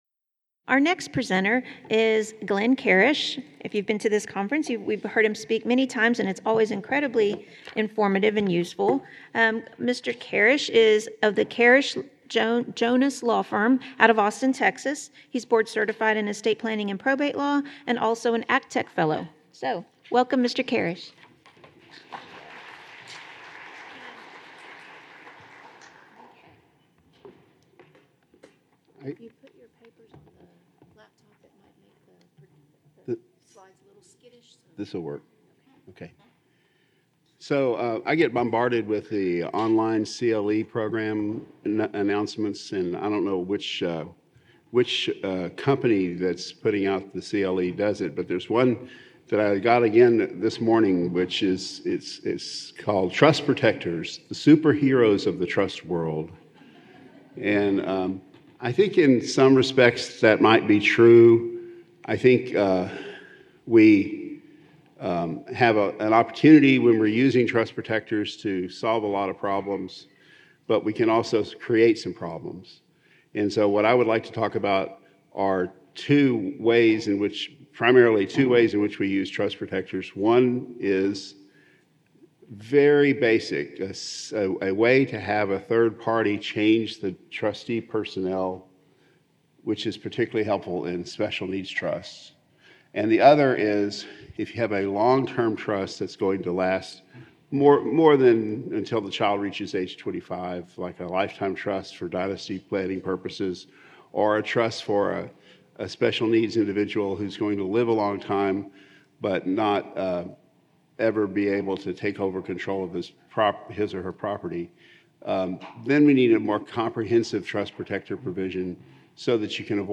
Originally presented: Aug 2024 Estate Planning, Guardianship and Elder Law Conference